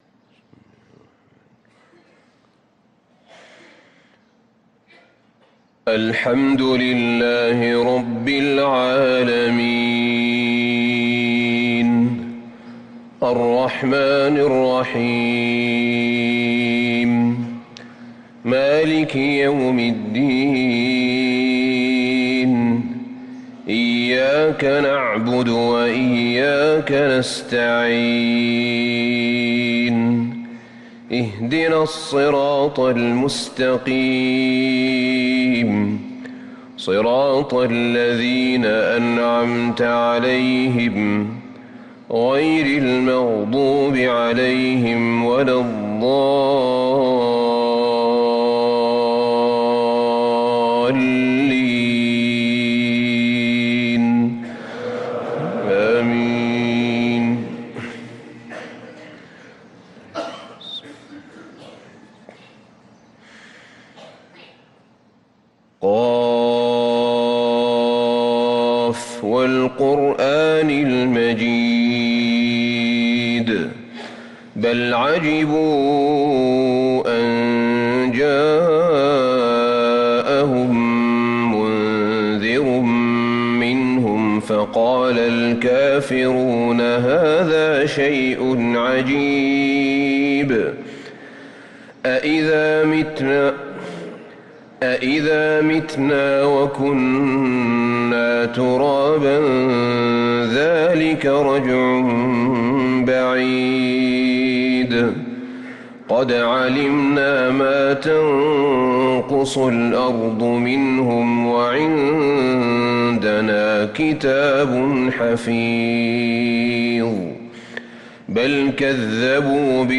صلاة الفجر للقارئ أحمد بن طالب حميد 4 محرم 1445 هـ